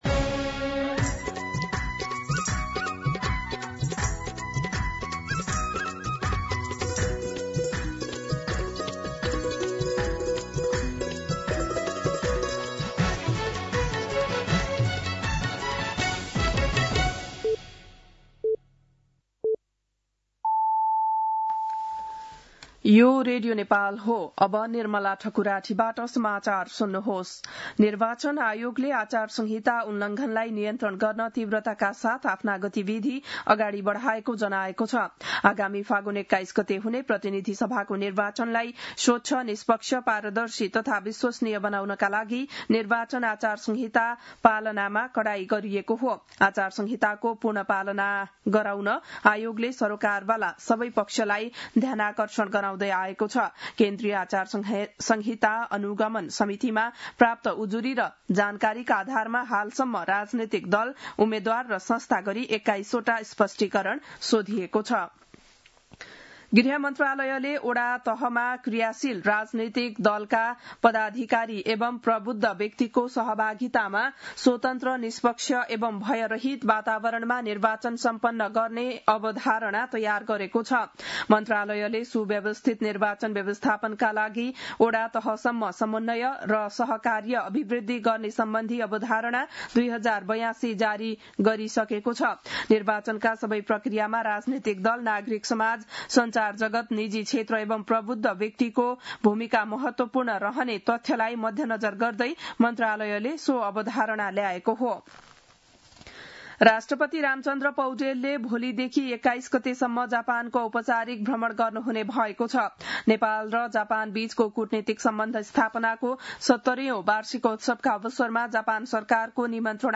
बिहान ११ बजेको नेपाली समाचार : १७ माघ , २०८२
11am-News-17.mp3